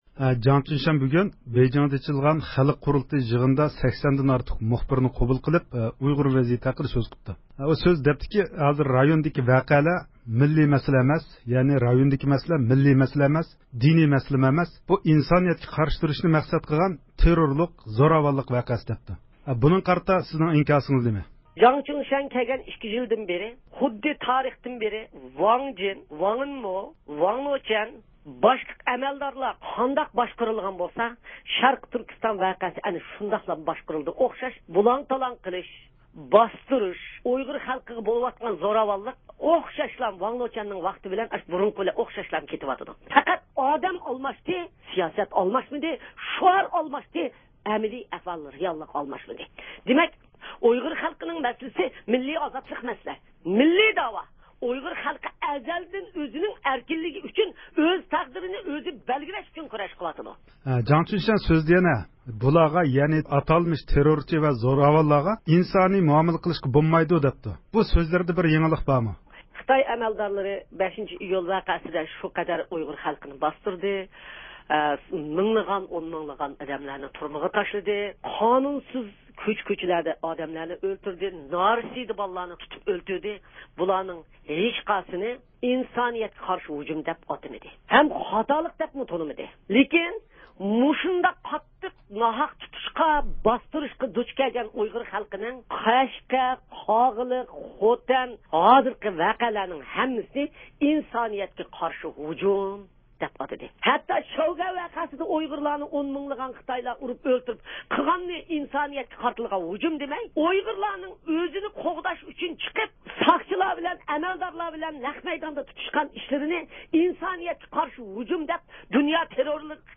ئۇيغۇر مىللىي ھەرىكىتى رەھبىرى رابىيە قادىر خانىم، بۈگۈن بۇ مۇناسىۋەت بىلەن رادىئويىمىزنىڭ زىيارىتىنى قوبۇل قىلىپ، جاڭ چۈنشيەن يۇقىرىقى سۆزلىرىگە ئىنكاس قايتۇردى.